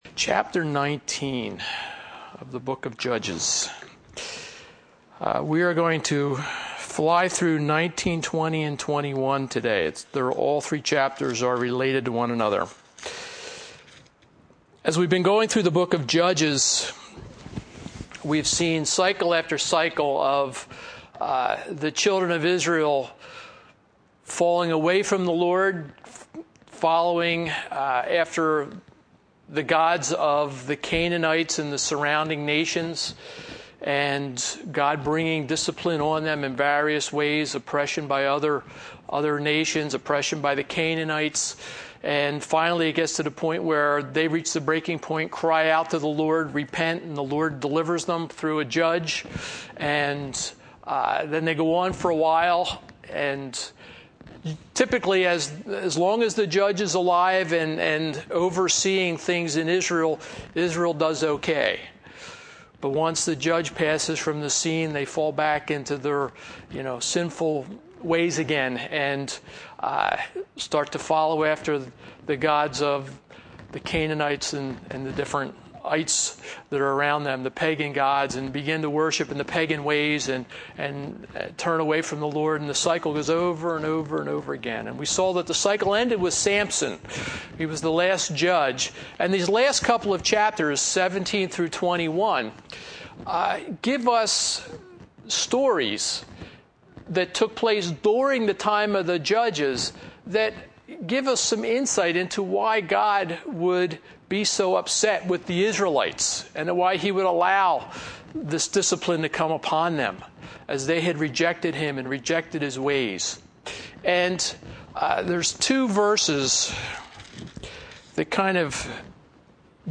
Judges 19-21 | Providence Calvary Chapel